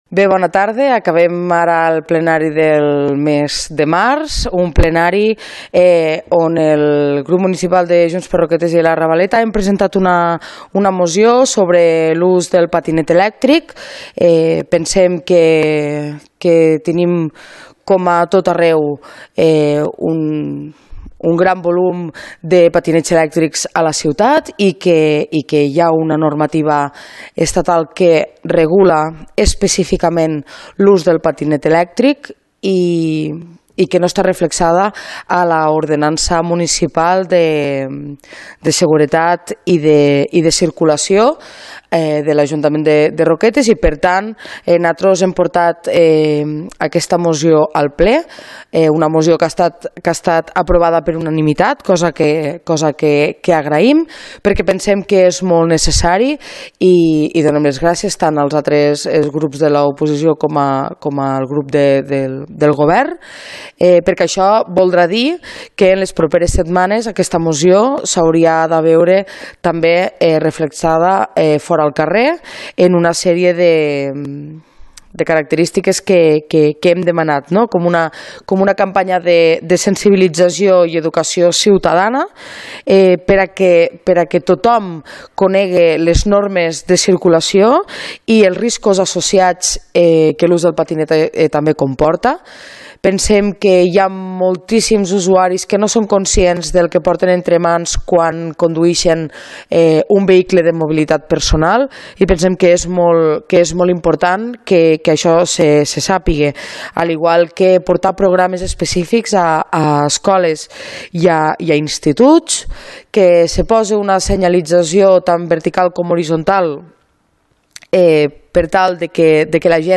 Ple Ordinari de Roquetes – Març 2025 – Declaracions – Junts – Lídia Saura | Antena Caro - Roquetes comunicació